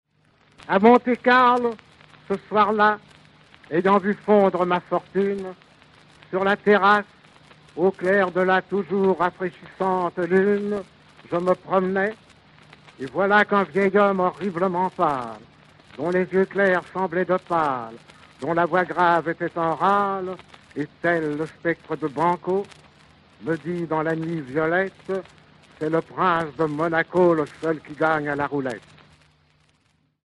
Maurice Donnay dit le début de son poème Monte-Carlo
(document radiophonique)
Donnay - document radiophonique.mp3